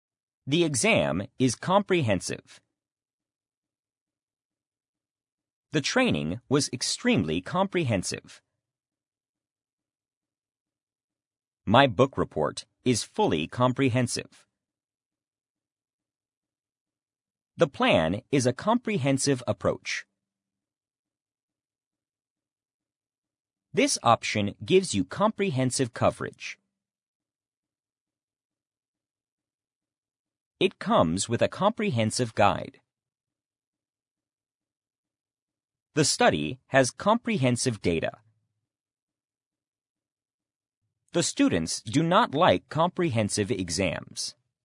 comprehensive-pause.mp3